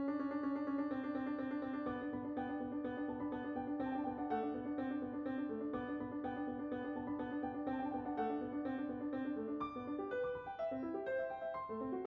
右手は優雅で繊細、そして軽くスタッカートで予告をしてトリルでその軽快さを際立たせて休符で次の変化を仄めかします。
一方、左手は水面の波紋のようにさらさら軽快な音を補佐しつつ、右手のトリルの山場と同時に変化に切り替わります。